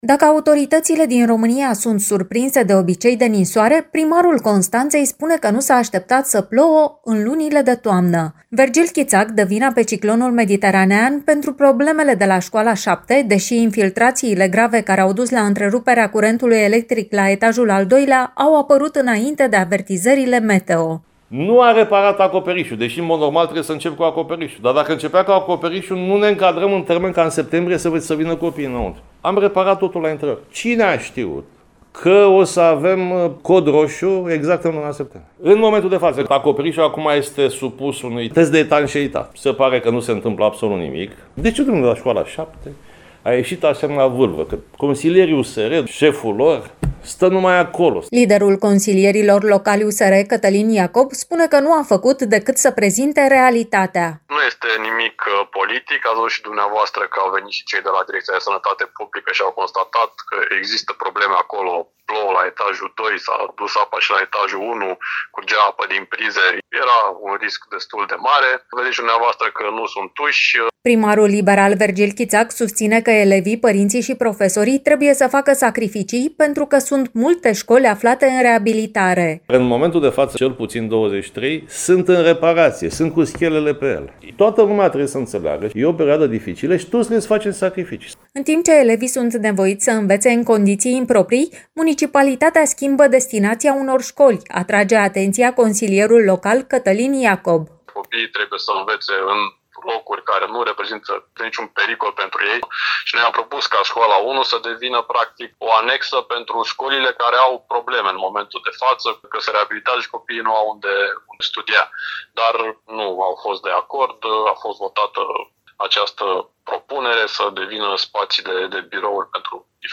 Primarul Vergil Chițac recunoaște, senin, că a fost de acord ca lucrările la acoperiș să fie lăsate la sfârșit și că a fost luat prin surprindere de ploile de toamnă.